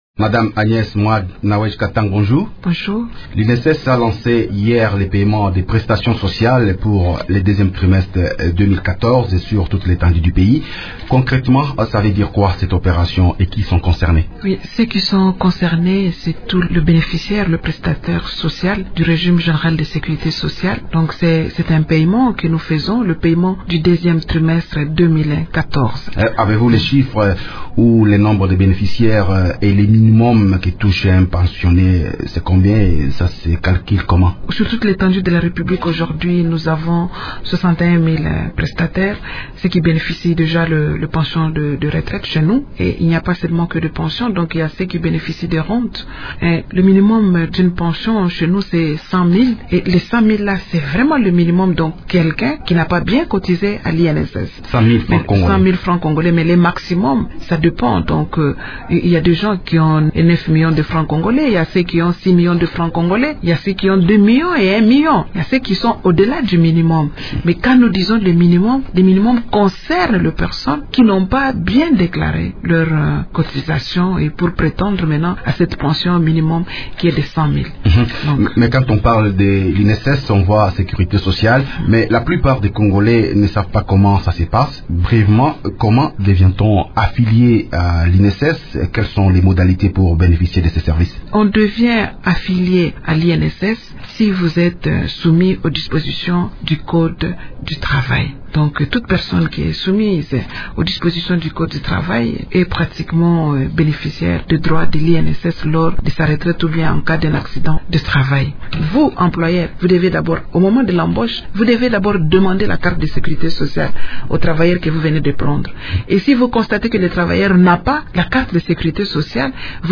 Agnès Mwad Nawej Katang, DG a.i de l’INSS le 2/07/2014 au studio de Radio Okapi à Kinshasa/ Ph.
Agnès Mwad Nawej Katang, Administratrice directrice générale intérimaire de l’Institut national de sécurité sociale (INSS), est l’invitée de Radio Okapi ce jeudi 3 juillet.